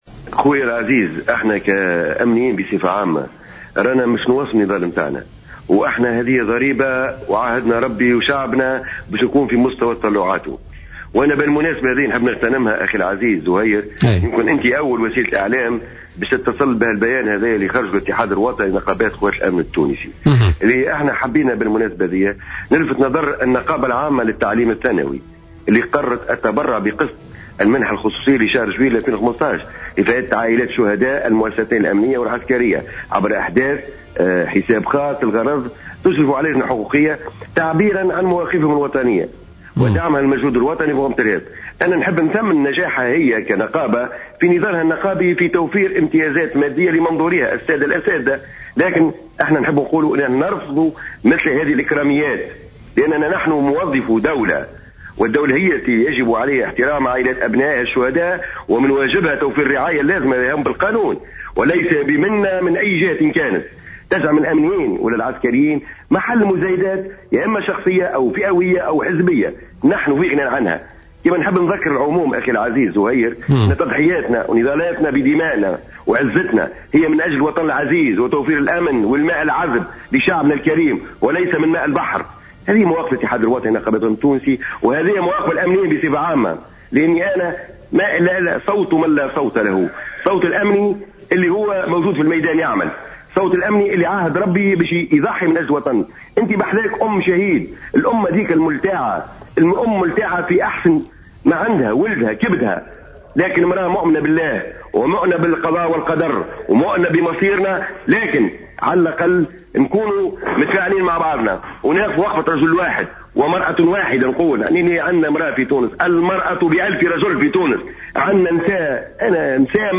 في تصريح للجوهرة أف أم اليوم خلال حصة بوليتيكا